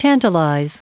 Prononciation du mot tantalize en anglais (fichier audio)
Prononciation du mot : tantalize